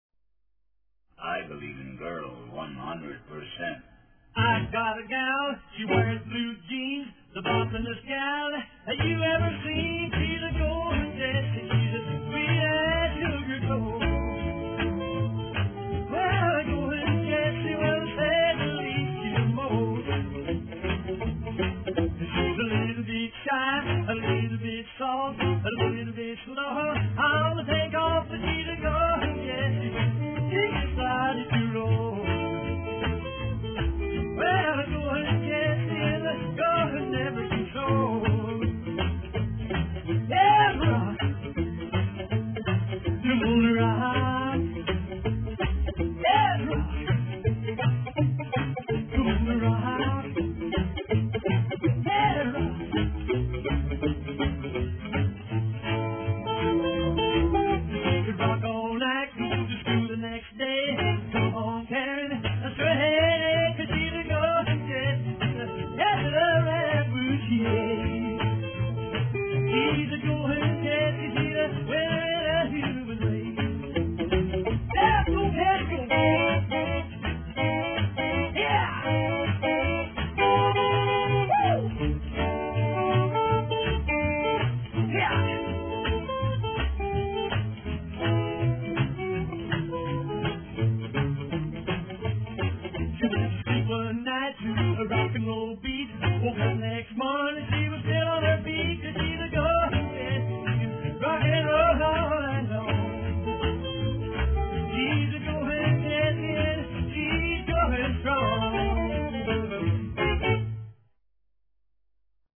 rockabilly singles